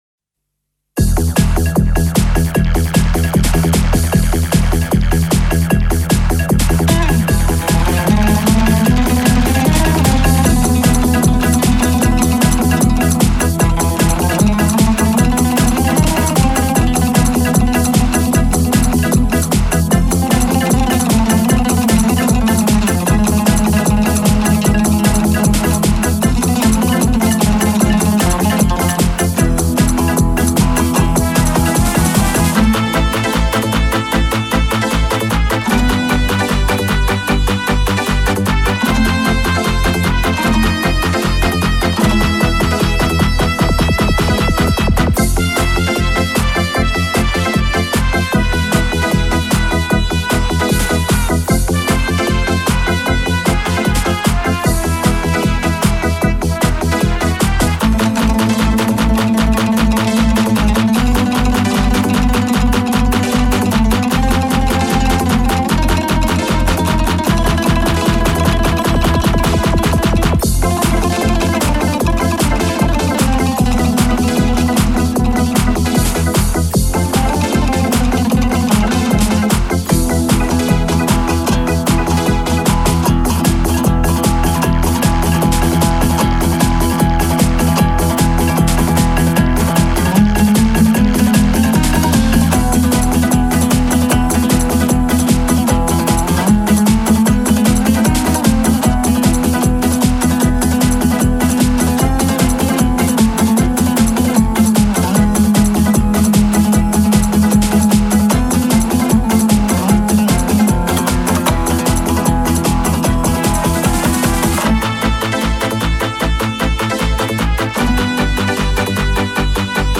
это трек в жанре поп-фолк